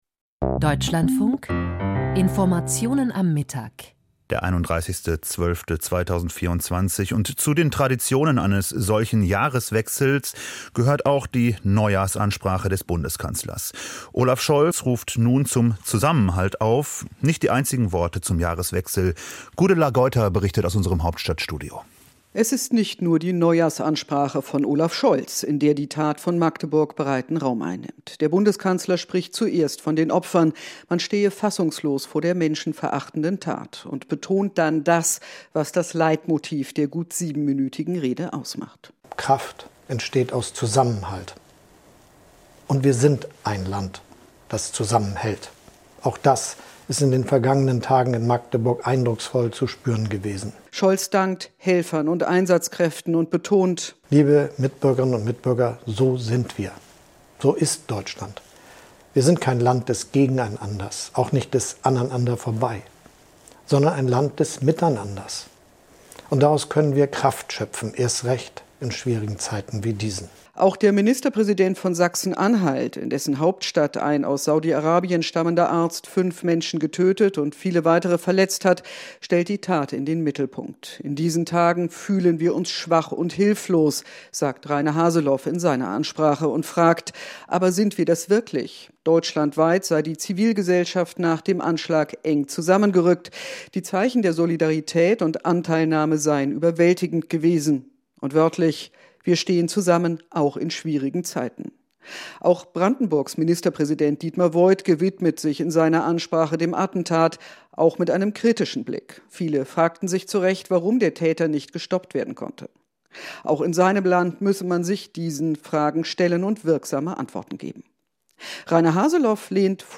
Neujahrsansprache